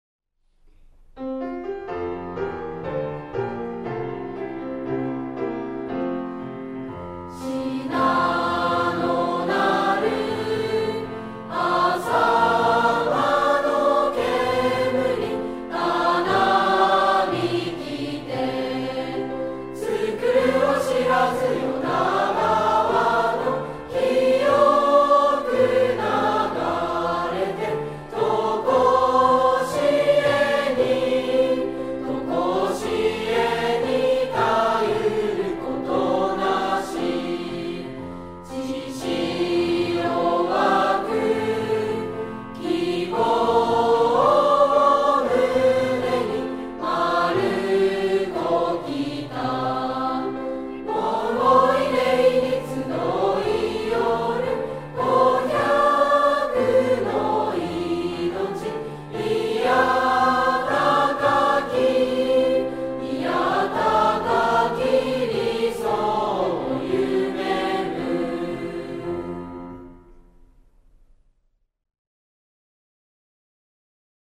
18年度３学年の皆さんの合唱です｡